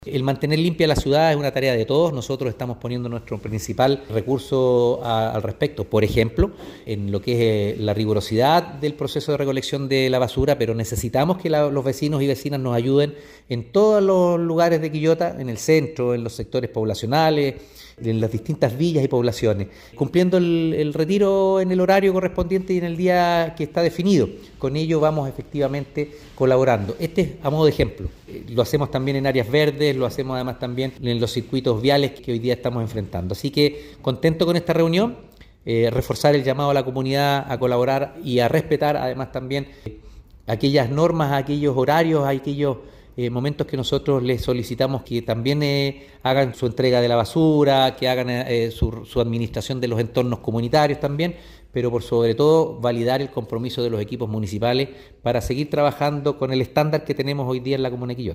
Alcalde-Oscar-Calderon-Sanchez-1-2.mp3